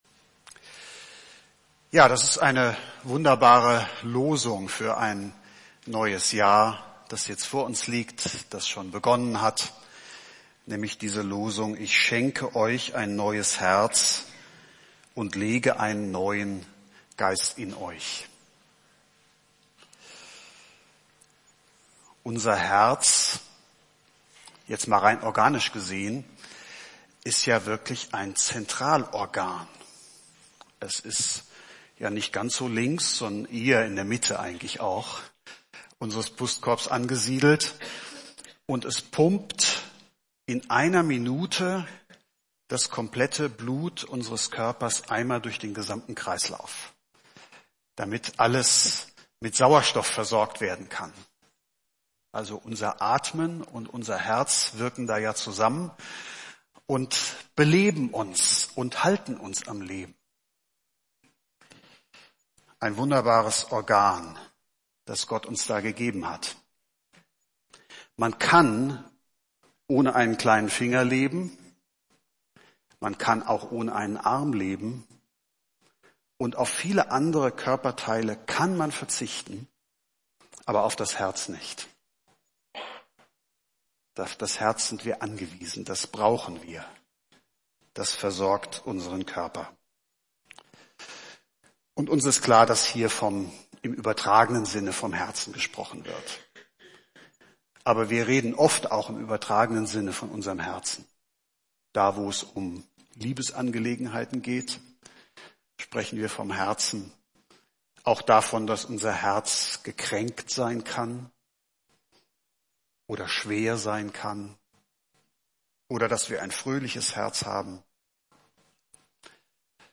Gottesdienst
Predigt zur Jahreslosung 2017